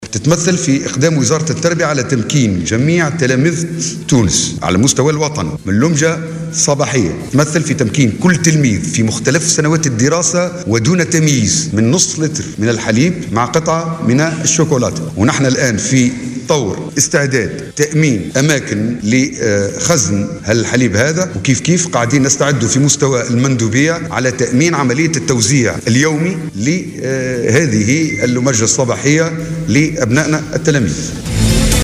وقال على هامش ندوة صحفية عقدها أمس في المهدية وواكبتها "الجوهرة أف أم"، إنه سيتم تمكين كل تلميذ من دون تمييز وفي مختلف سنوات الدراسة من نصف لتر حليب وقطعة شوكولاطة في الوجبات الصباحية لكل تلميذ بمدرسته.